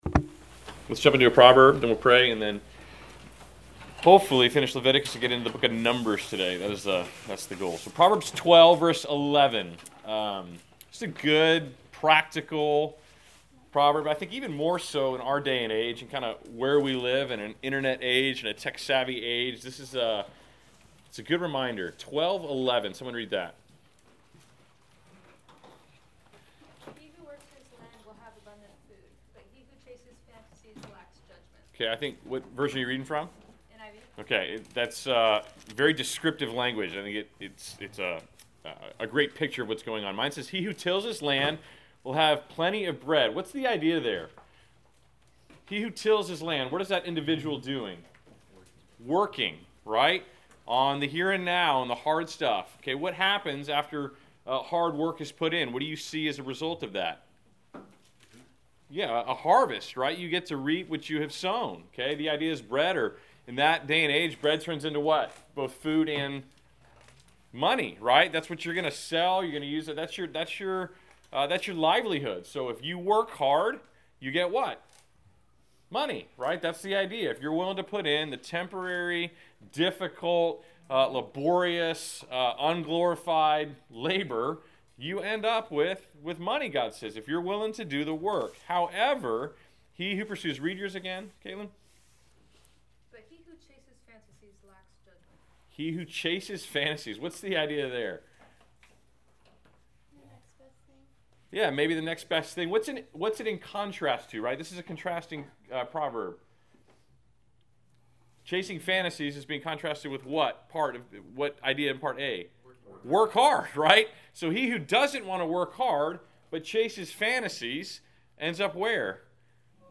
Class Session Audio November 19